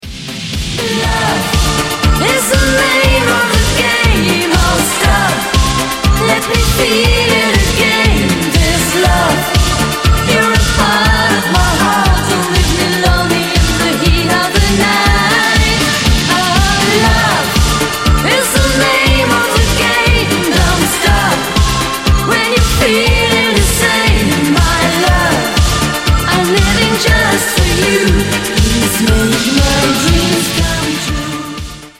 Ретро рингтоны